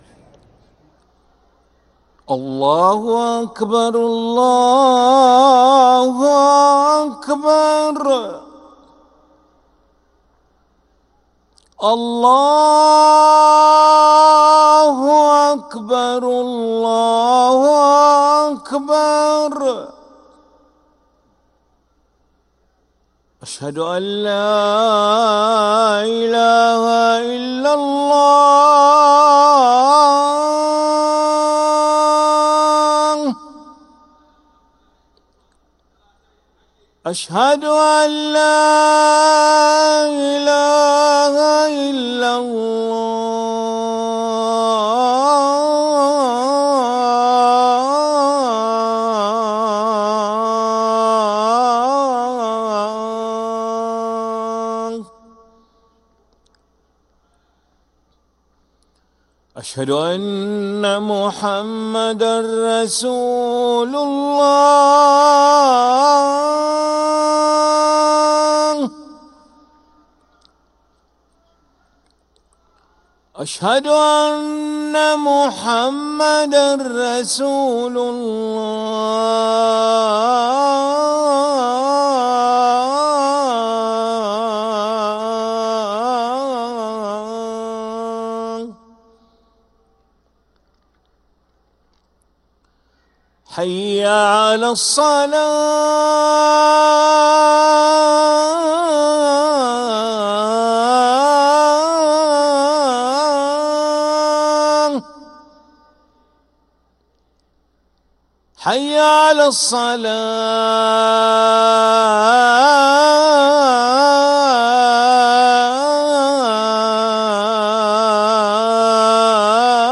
أذان العشاء للمؤذن علي ملا الأحد 21 صفر 1446هـ > ١٤٤٦ 🕋 > ركن الأذان 🕋 > المزيد - تلاوات الحرمين